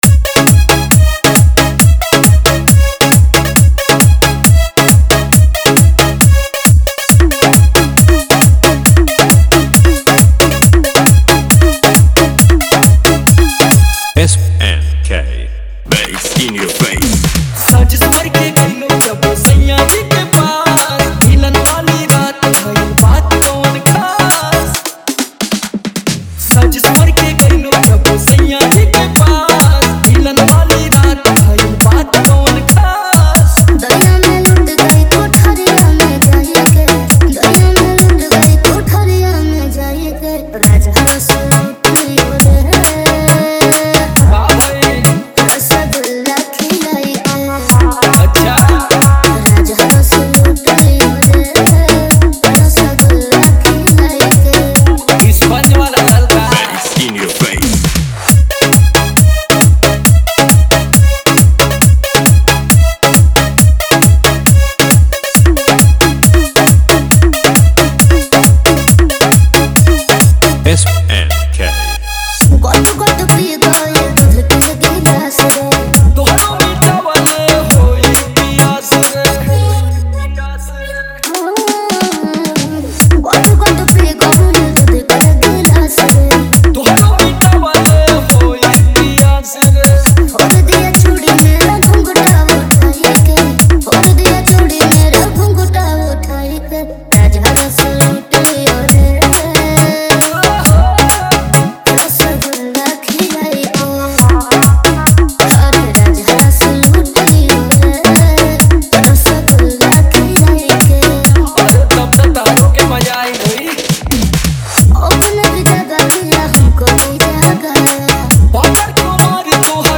Bhojpuri Dj Songs